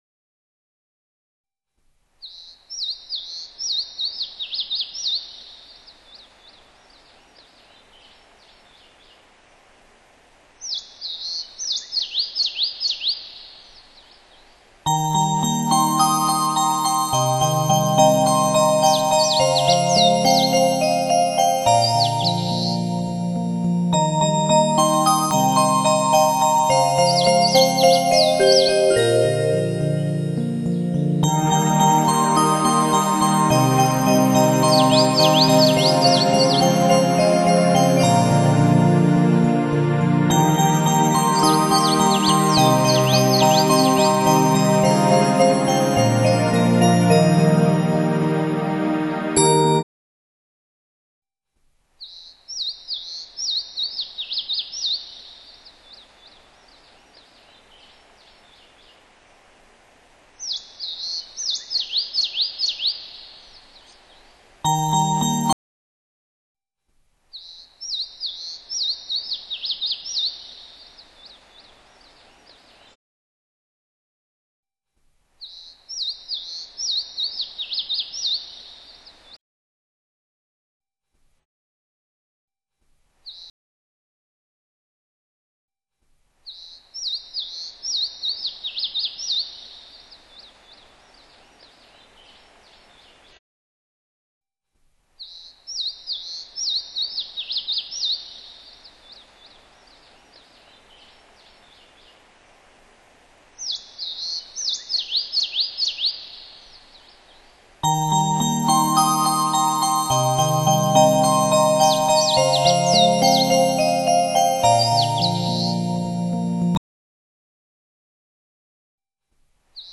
梵音的澄澈、轻扬，让心的忧容褪色、雾化、流进大海，遁入虚空，清凉剔透的水晶，返璞归真的天籁，如香汤沐浴、似甘露灌顶、纾解胸中沉积不散的郁闷，扫除心中许久以来的阴霾，身心自在，忘却忧伤......